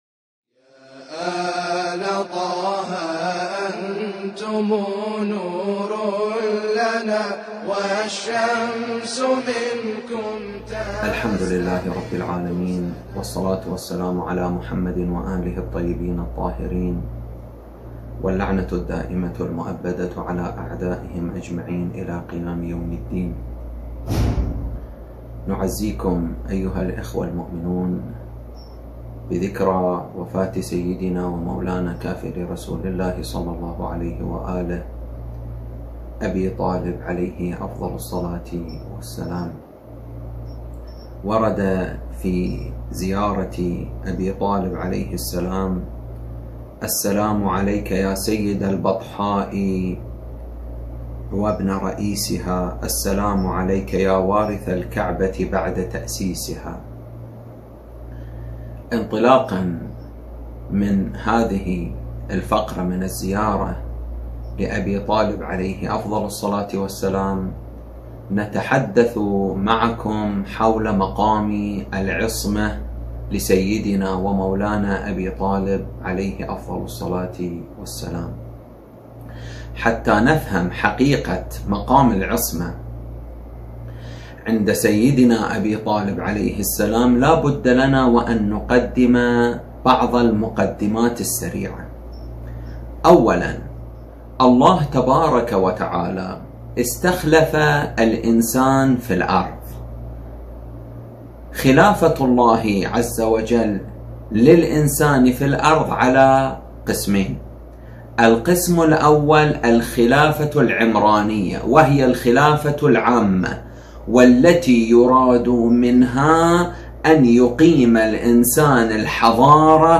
كلمة بمناسبة ذكرى وفاة أبي طالب عليه السلام